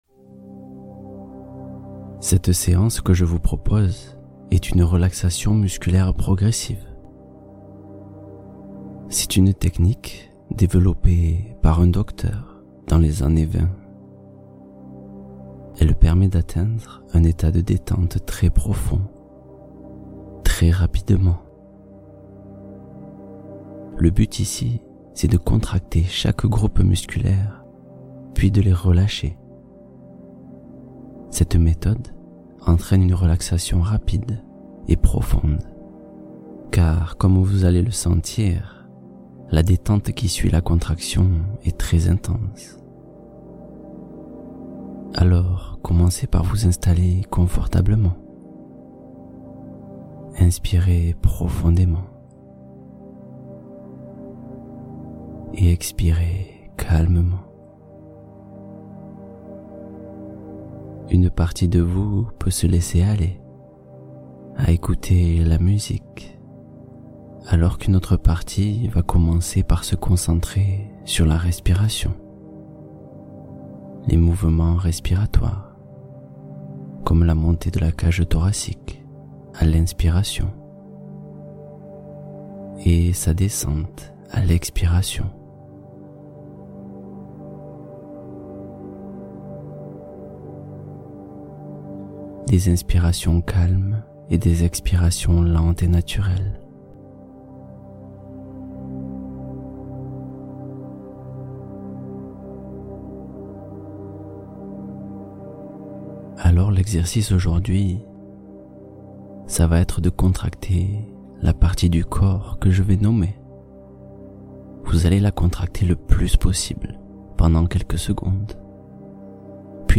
Détente Musculaire : 15 minutes de relaxation progressive pour le corps